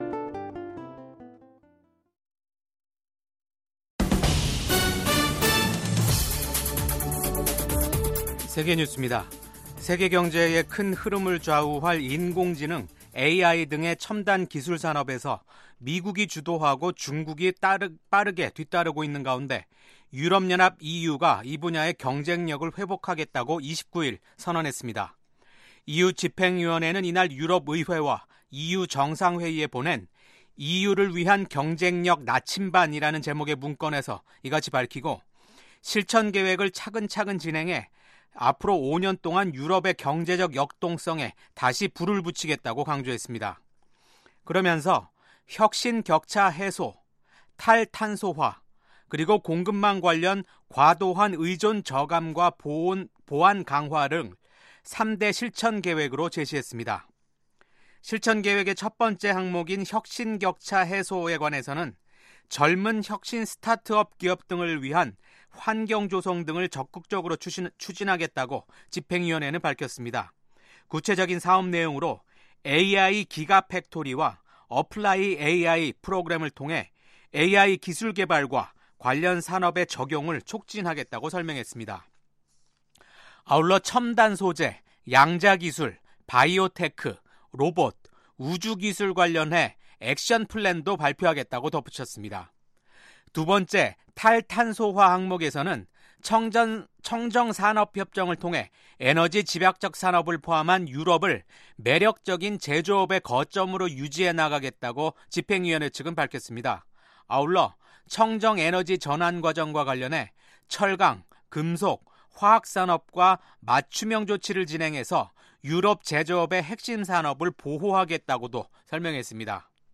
VOA 한국어 아침 뉴스 프로그램 '워싱턴 뉴스 광장'입니다. 미국 백악관은 ‘북한의 완전한 비핵화’가 여전히 트럼프 행정부의 목표라고 확인했습니다. 도널드 트럼프 대통령이 미국의 차세대 미사일 방어 시스템 개발을 촉구하는 행정명령에 서명했습니다. 김정은 북한 국무위원장은 핵 대응 태세를 무한히 강화한다는 입장을 거듭 밝혔습니다.